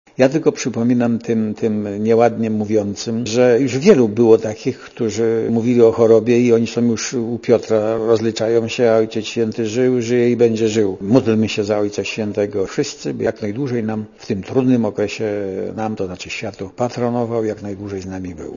Ojciec Święty jest silny, a jedyne, co my możemy zrobić - mówi Radiu ZET Lech Wałęsa - to modlić się za niego. Byłemu prezydentowi nie podoba się cała "histeria" wokół choroby Jana Pawła II.
Komentarz audio